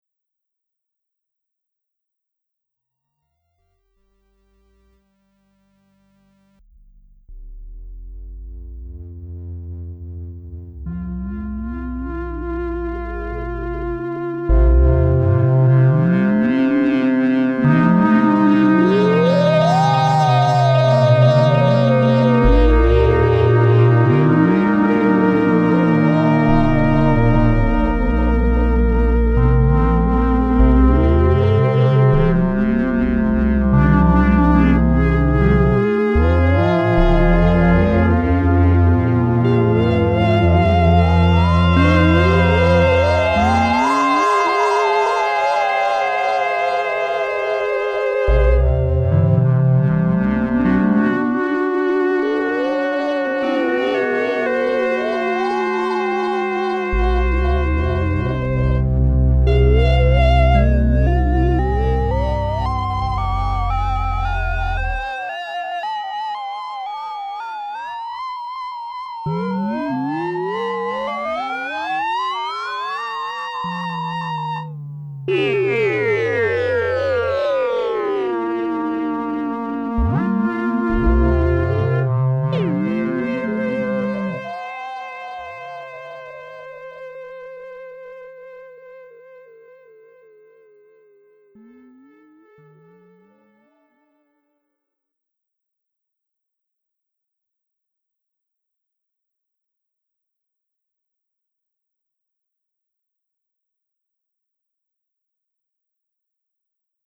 SOUND cool sounding leads, basses and sweeps.. rich modulations and dynamic, not to go on stage, it's a bit hard to repair (and sometimes it need repair..)
little Synthpop Demo - Synthesizer-Magazin #8
Rhodes Chroma FM Demo
chroma_FMableep.mp3